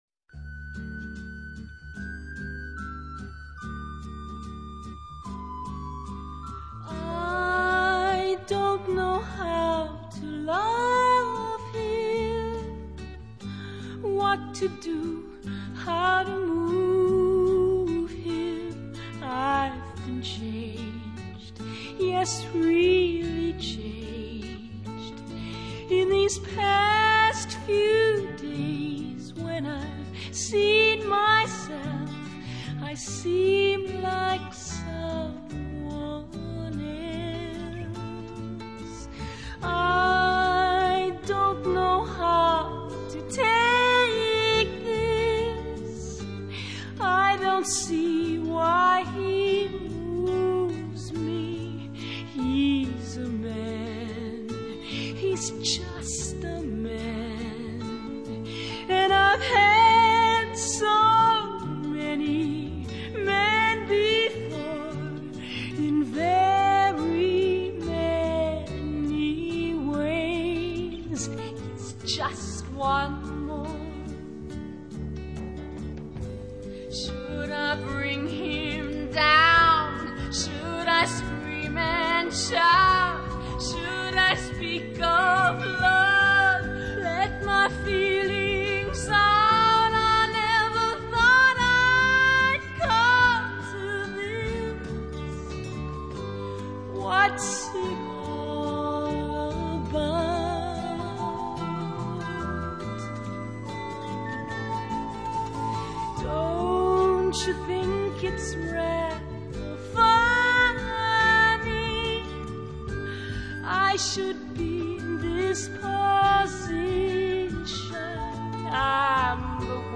不過也許是由於在曠野裡拍戲，加上年代較為古老，因此錄音不能太要求…這個版本有一首歌是獨有的：Pharisee 人唱的